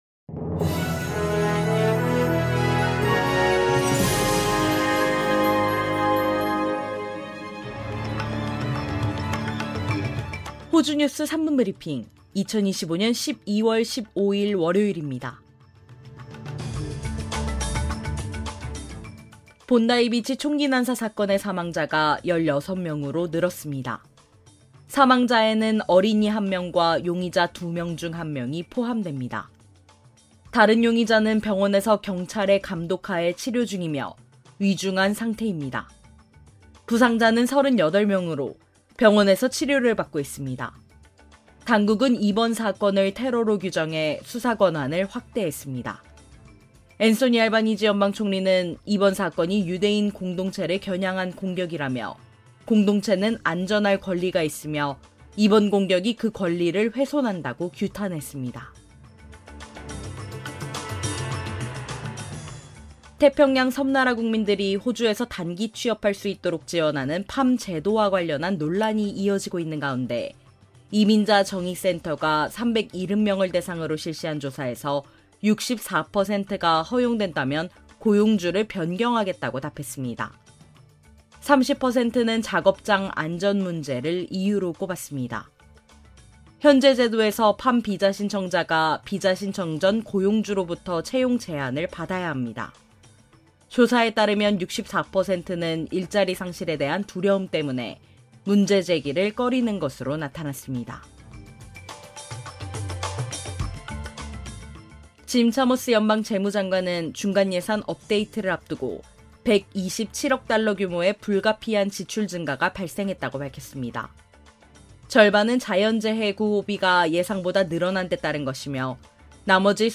호주 뉴스 3분 브리핑: 2025년 12월 15일 월요일